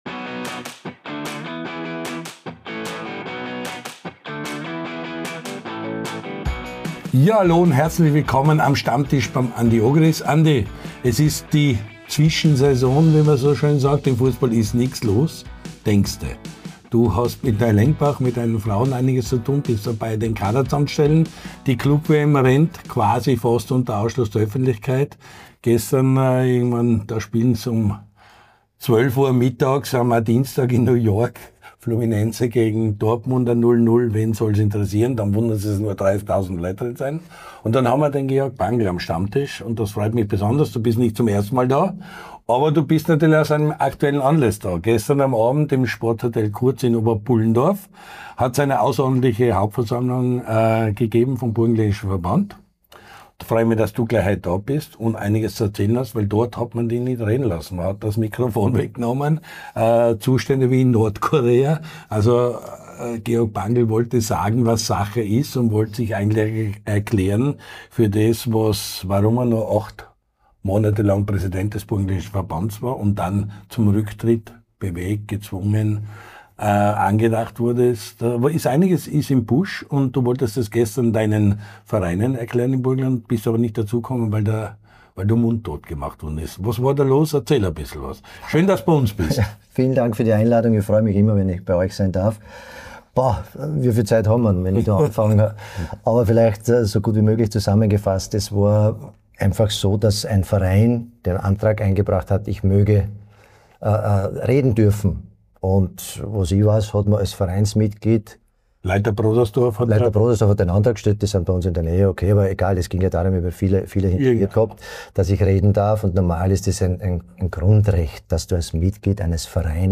Der LAOLA1-Kulttalk mit Andy Ogris